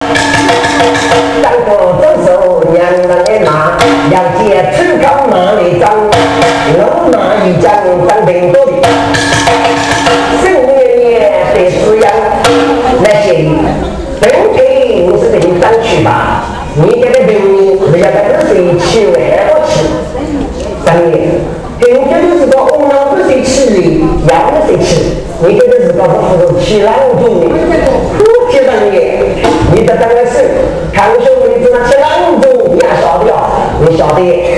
Wenzhou "Guci"
a form of ballads and story-telling